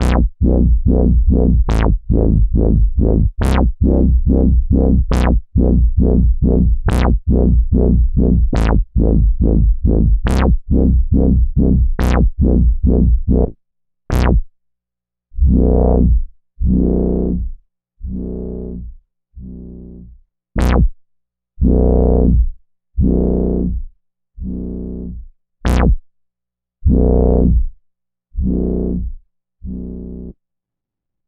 I play a single preset and experiment with the expression pedal, an Ernie Ball VP JR 25K which is not a perfect match for the unit but seems to work fairly well. Note you still get the envelope sound, if the preset has one, then you can cycle through the filter frequency with the expression.
Korg_G5_expression_01.mp3